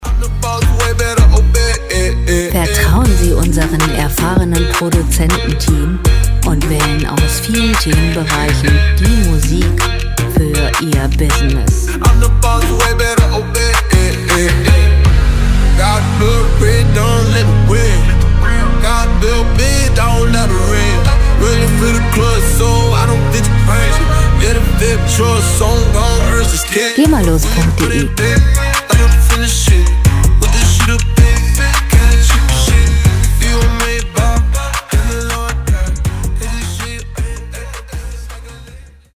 Musikstil: Hip-Hop
Tempo: 80 bpm
Tonart: H-Moll
Charakter: arrogant, selbstbewusst
Instrumentierung: Synthesizer, Amerikanischer Rap